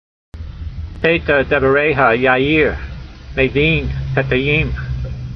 Sound (Psalm 119:130) Transliteration: pay tah deva r ey ha ya' eer , may veen peta' y eem Vocabulary Guide: The door -opening of your word s gives light , making the simple understand . Translation: The door -opening of your words gives light, making the simple understand.